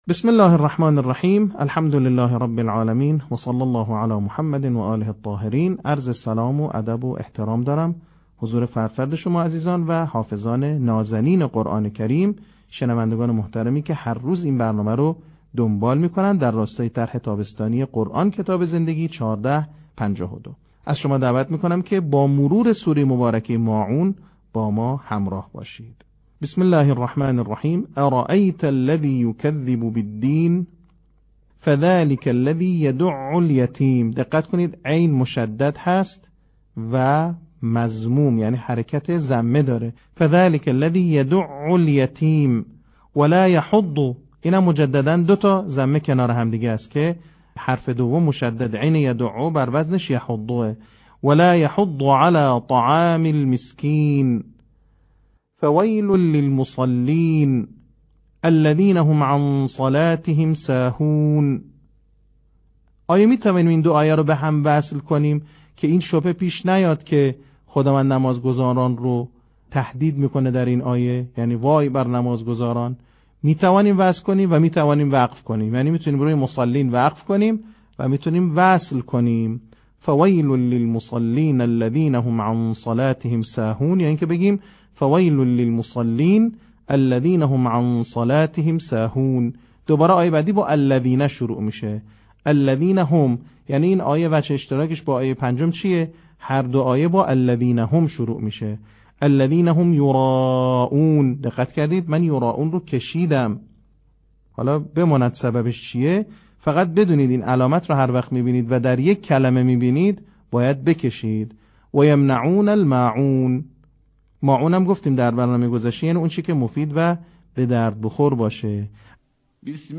صوت | نکات آموزشی حفظ سوره ماعون